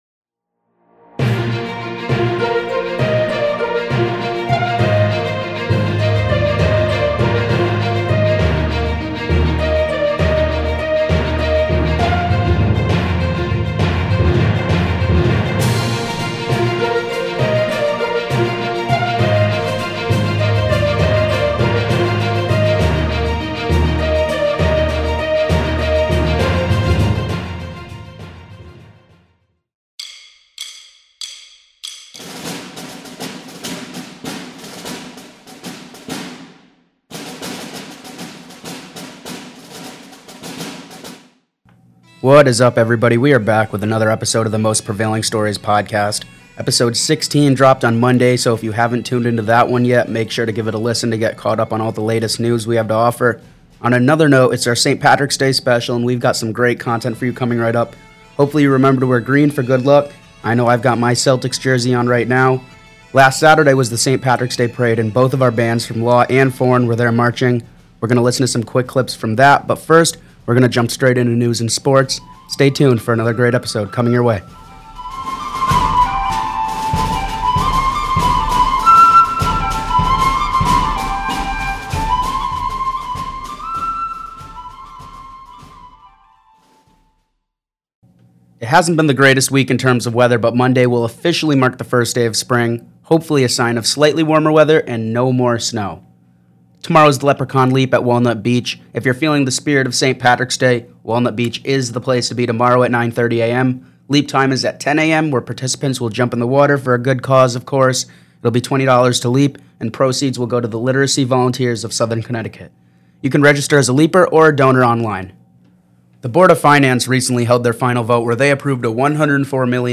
In this episode we cover all the latest news (1:28), including information on the board of finance's vote on our budget for education operations and what to expect next (1:55), sports (3:19), and then we play some quick clips from the Milford's Saint Patrick's Day Parade last Saturday (5:13).